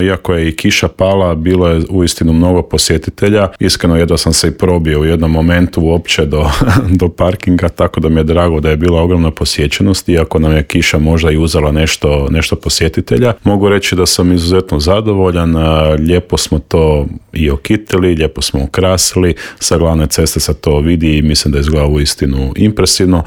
Kakva je ponuda i što sve čeka posjetitelje u Intervjuu Media servisa otkrio nam je tamošnji gradonačelnik Dario Zurovec.
Sve to na raspolaganju je građanima i posjetiteljima Svete Nedelje, a njihov gradonačelnik Dario Zurovec u Intervjuu Media servisa otkrio je kako je bilo na otvaranju: